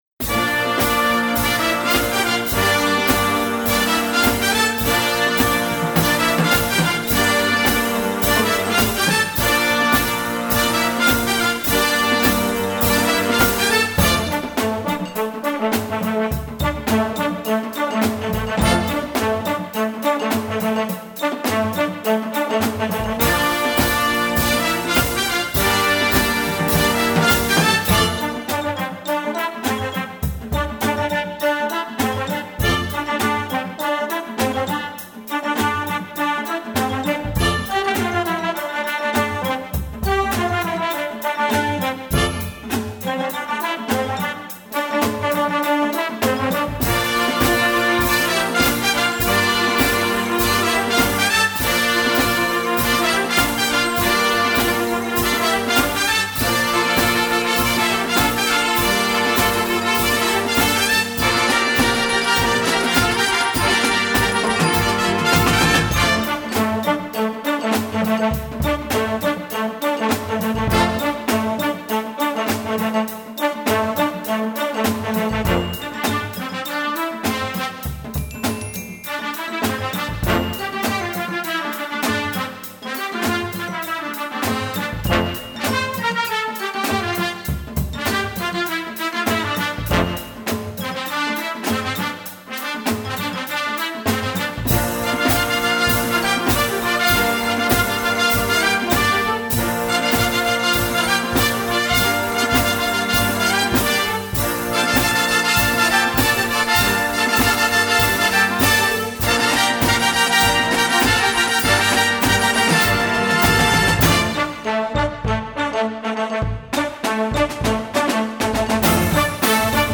17 x 13 cm Besetzung: Blasorchester Tonprobe